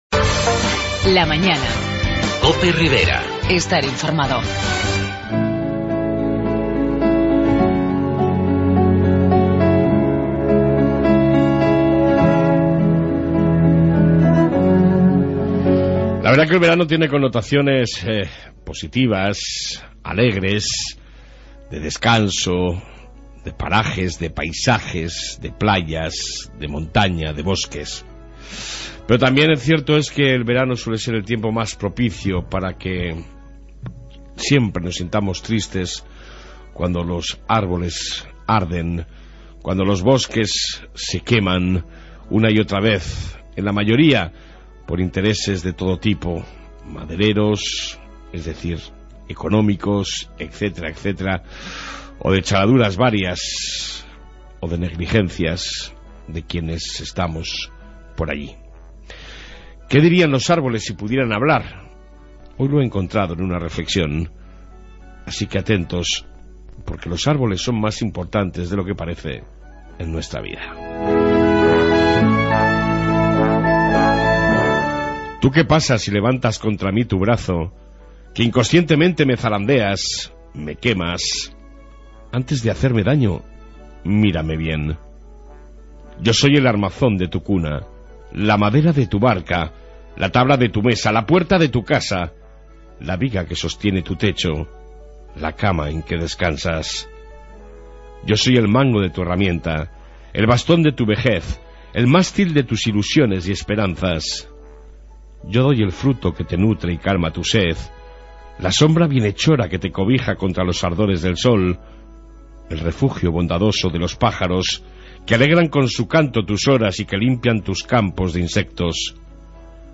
AUDIO: Aqui teneis la reflexión diaria, Noticias locales y Entrevista con APP Tudela.